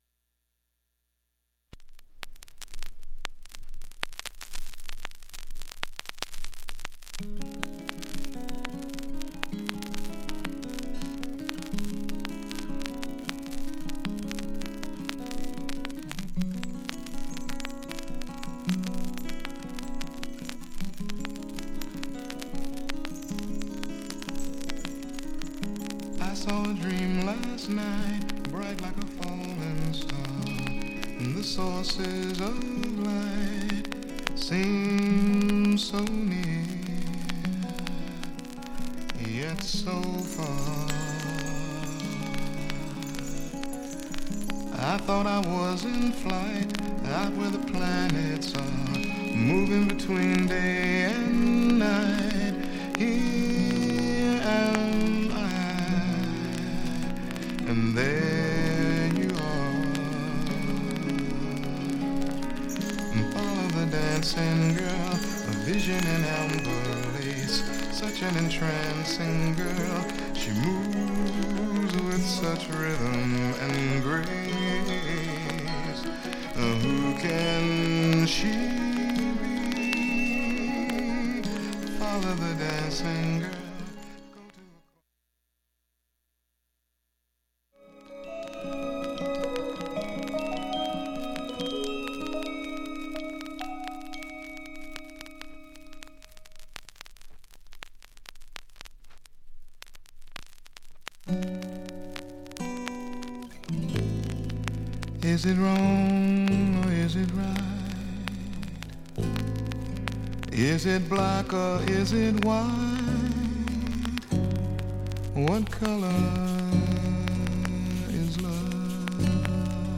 全体にかなり聴き取りにくいです。
静かな部でわずかに聴こえるレベルです。
B面は静かな部でもチリもかなり少なく
プツ音もかすかな5回程度数か所で
各面1曲目の始めがチリ大きい程度。
1,A-1序盤、A-2序盤などチリプツ少し大きめ。
2,(3m24s〜)B-2中盤かすかなプツが９回